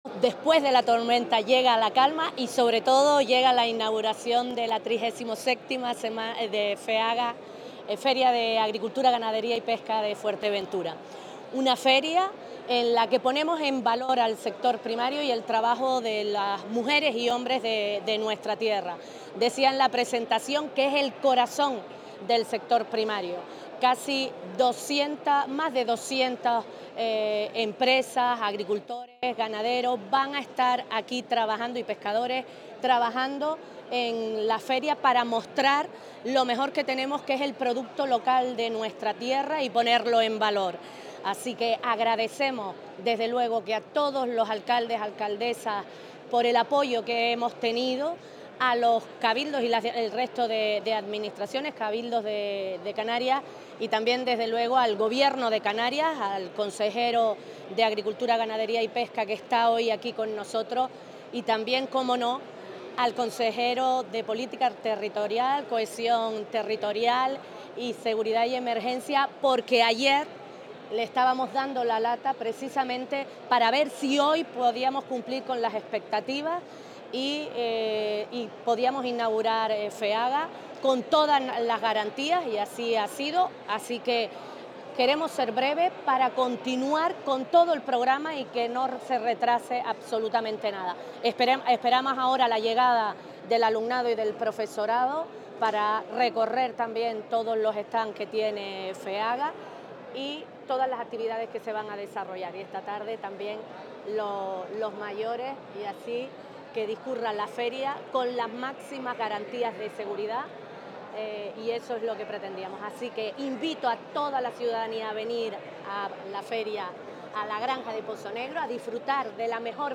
Entrevistas y declaraciones en Fuerteventura Digital
Inauguración de Feaga 2025